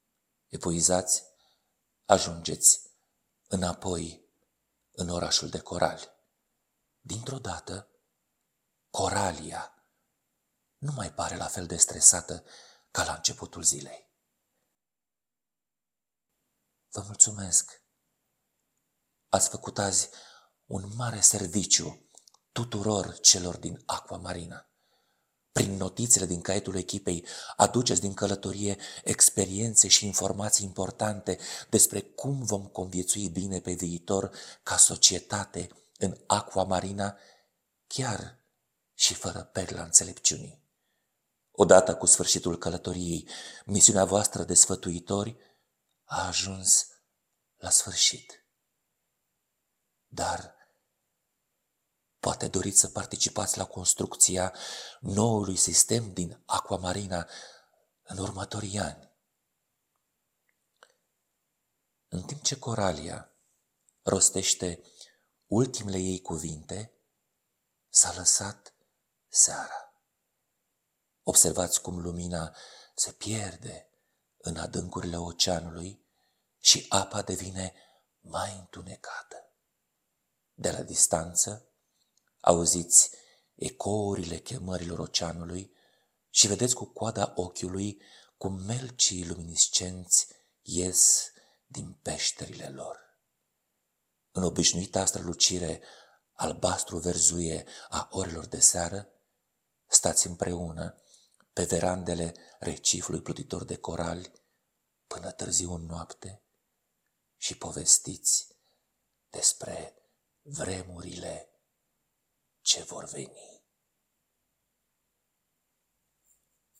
Conținutul nu este diferit, dar se recomandă povestea audio, deoarece prezintă mai viu atmosfera poveștii.